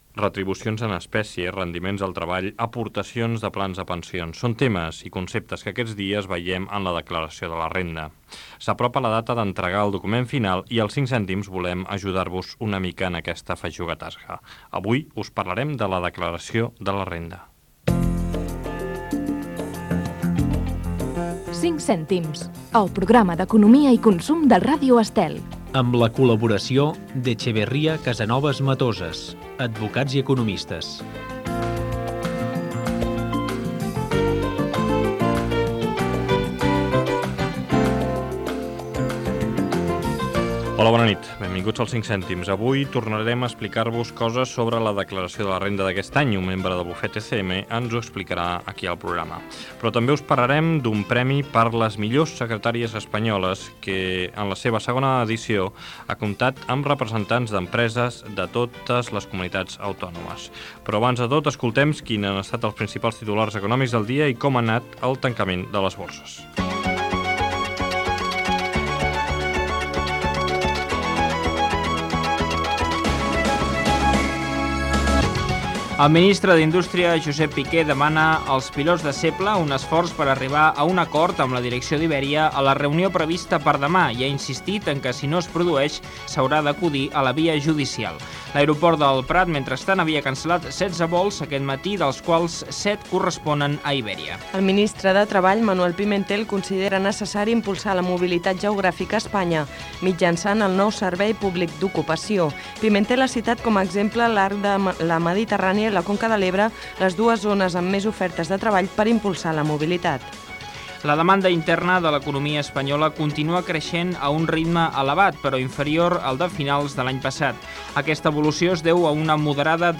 La declaració de la Renda, indicatiu del programa, sumari, notícies econòmiques, el tancament de les borses, indicatiu del programa, la millor secretària de l'any
FM